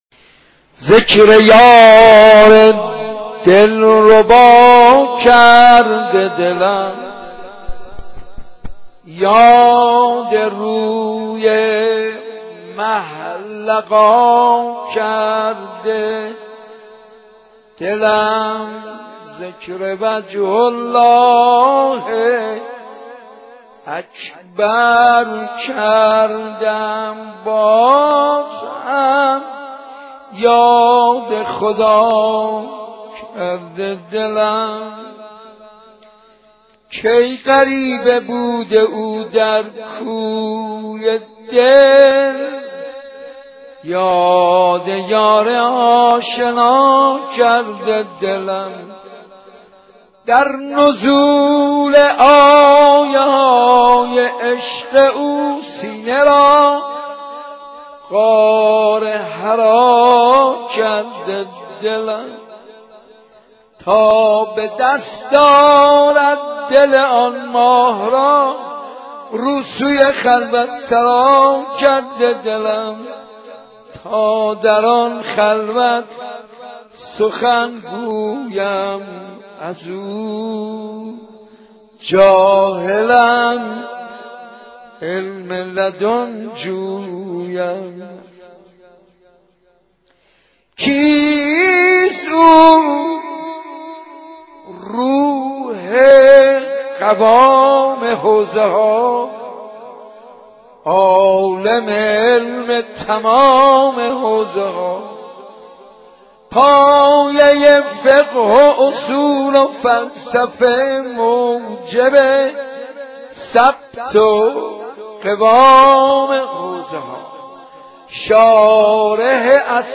صوت/ نوای حاج منصور ارضی به مناسبت میلاد امام باقر(ع)